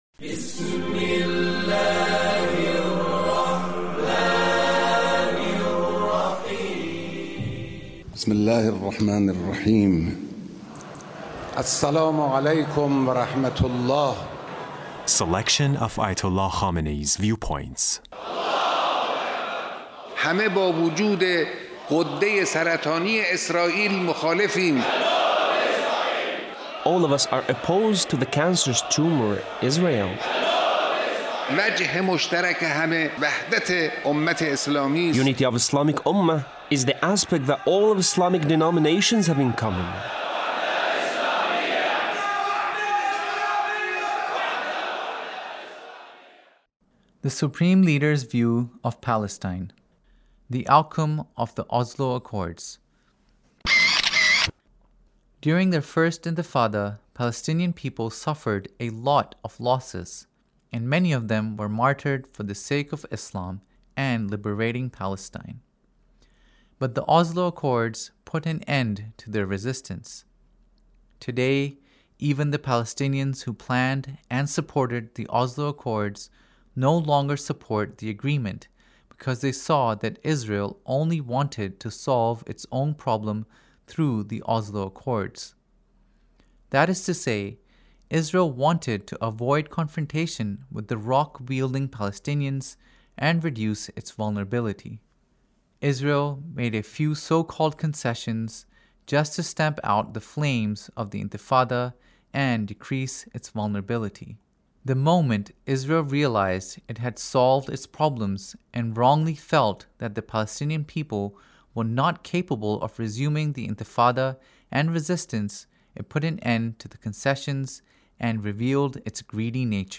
Leader's Speech on Palestine